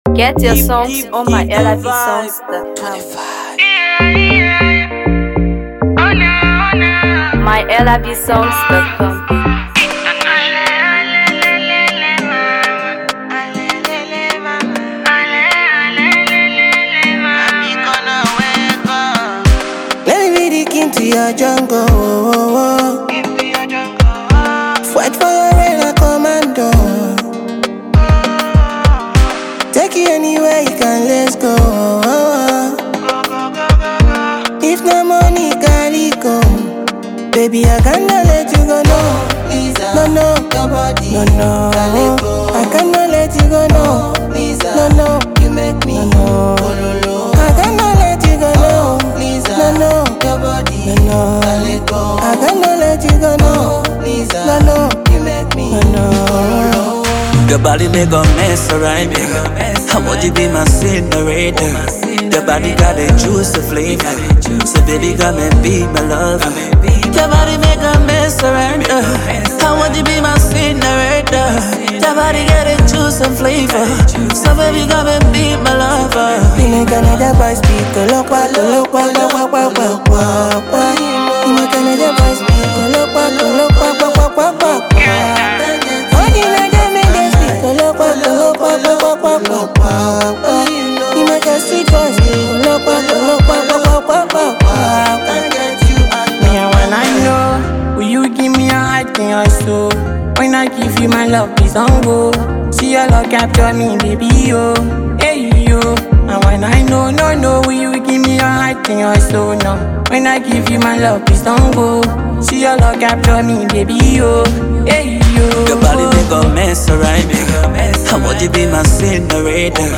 Afro PopDancehall